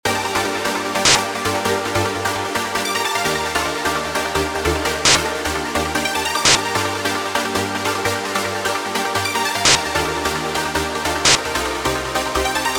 Я начал с этого трансового сэмпла, добавив 100-миллисекундные всплески белого шума в случайные моменты времени в аранжировке.
Я все еще слышу белый шум, но он напоминает мне, скажем, звук малого барабана или сэмпл тарелки, вместо полного прерывания звука, как в последних двух экспериментах.